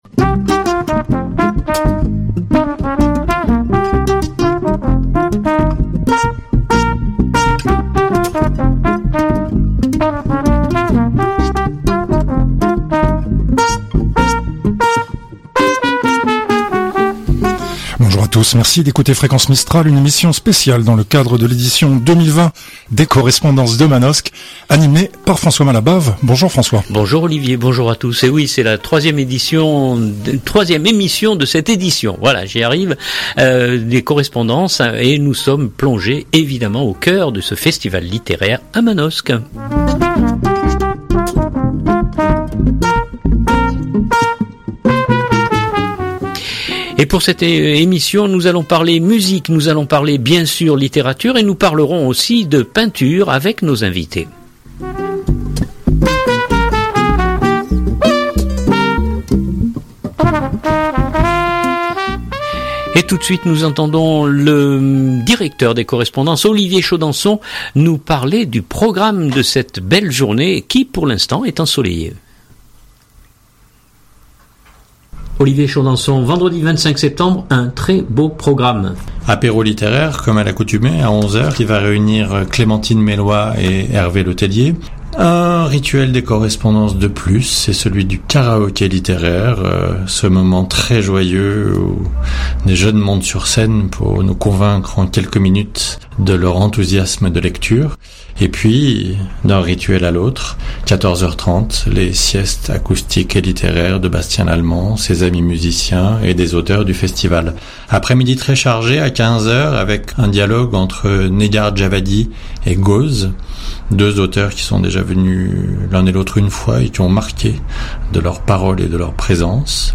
Chaque jour, Fréquence Mistral propose une émission en direct de Manosque au coeur du festival des Correspondances.